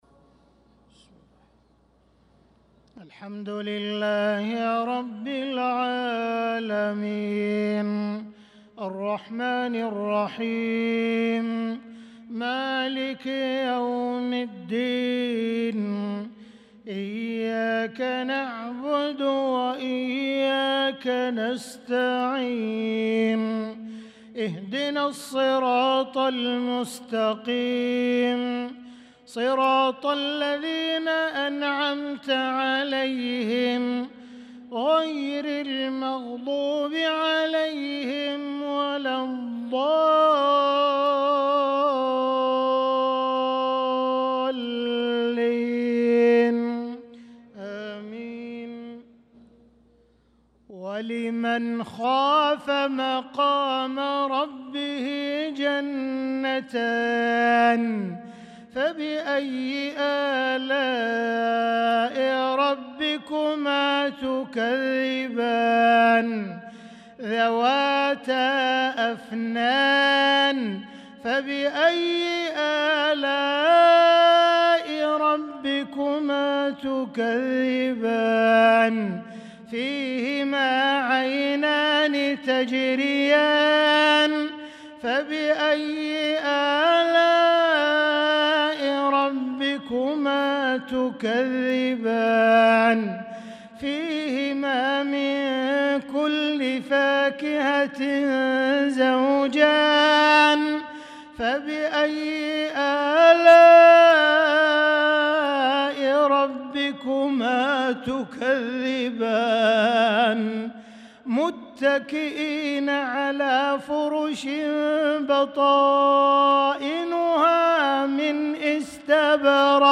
صلاة العشاء للقارئ عبدالرحمن السديس 24 شوال 1445 هـ
تِلَاوَات الْحَرَمَيْن .